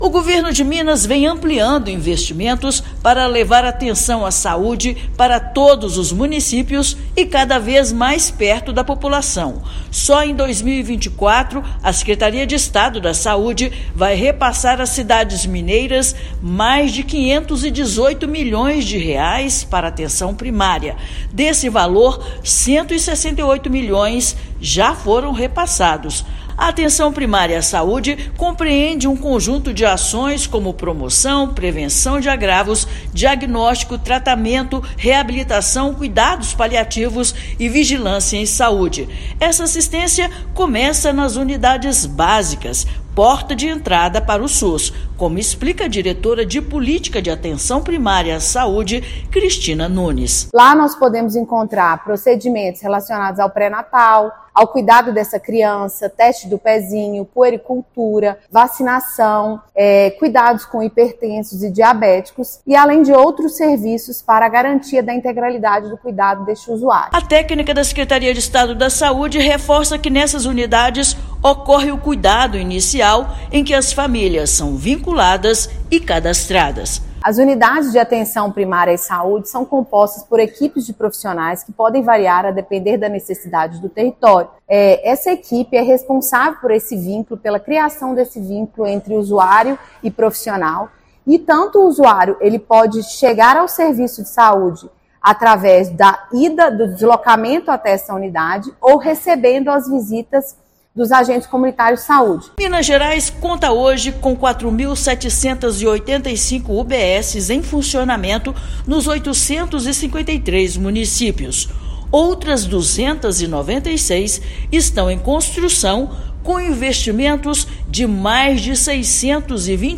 Balanço de ações destaca importância do atendimento multiprofissional e humanizado na Atenção Primária. Ouça matéria de rádio.